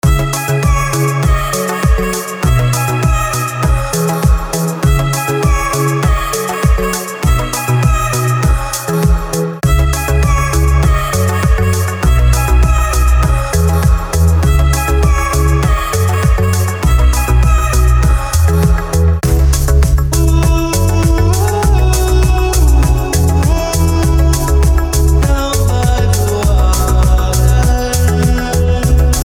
• Качество: 320, Stereo
ритмичные
громкие
веселые
nu disco
house